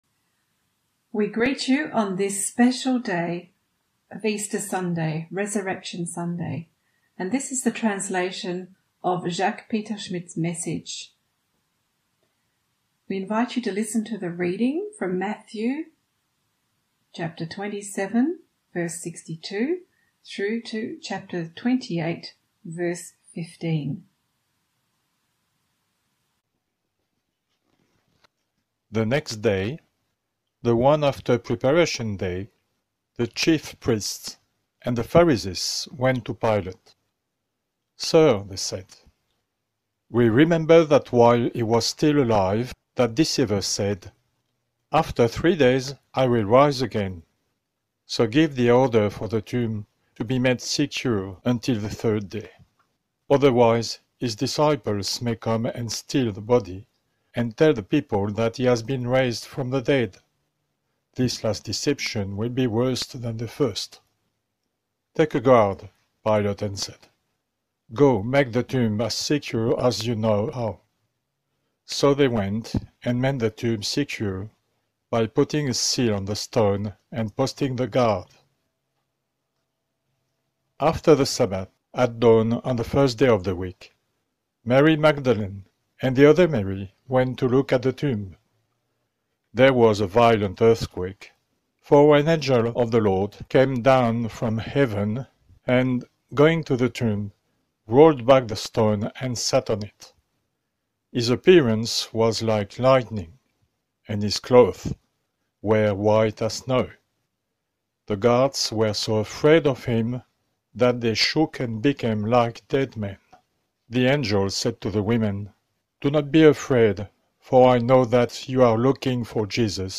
Sunday sermons Archives - Page 17 of 24 - FREE EVANGELICAL CHURCH BERGERAC